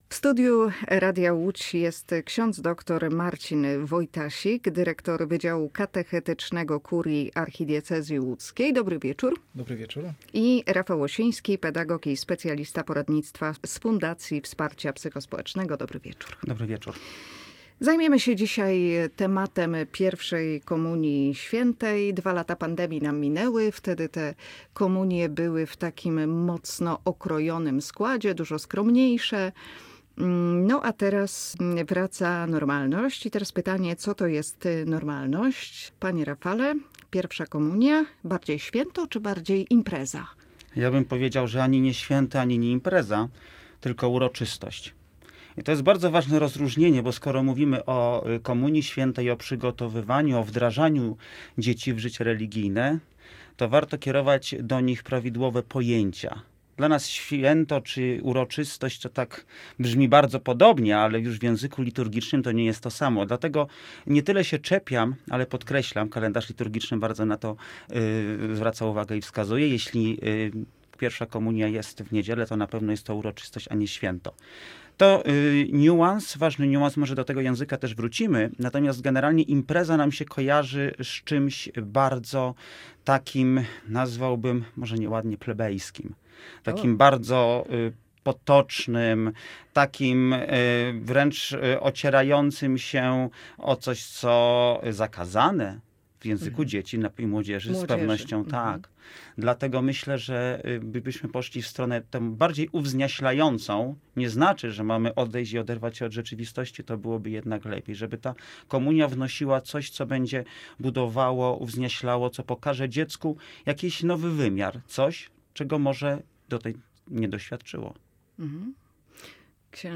Gośćmi Radia Łódź byli: